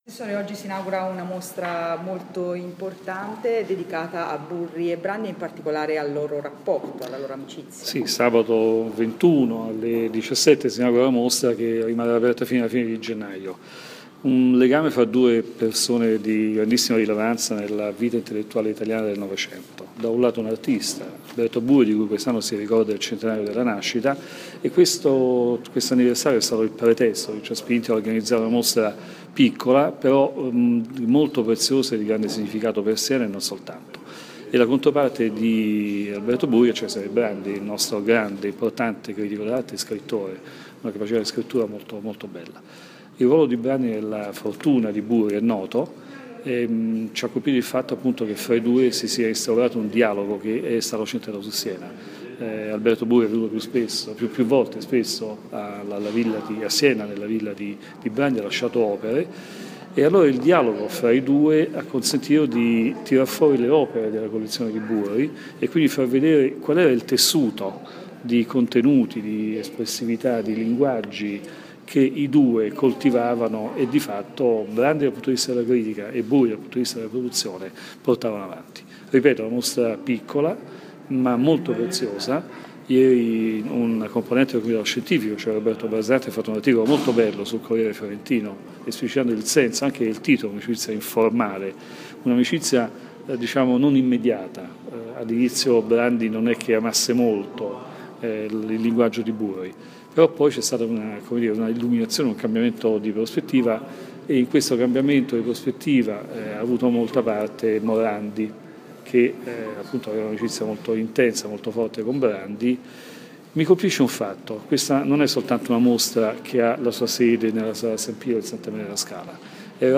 Massimo Vedovelli, assessore alla cultura del Comune di Siena